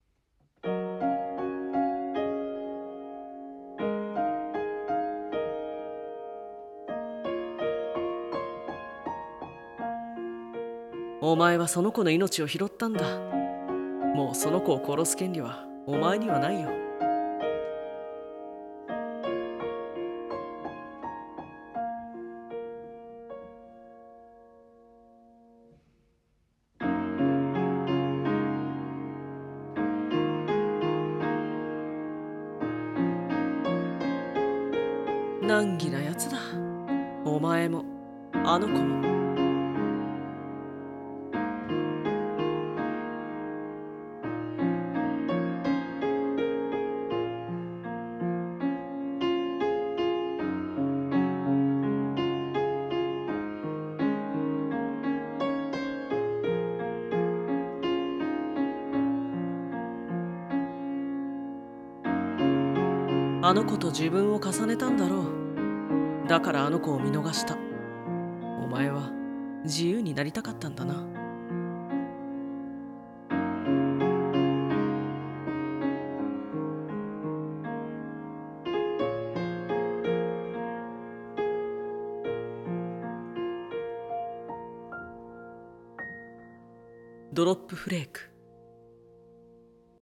【声劇/3人用CM風台本】ドロップフレーク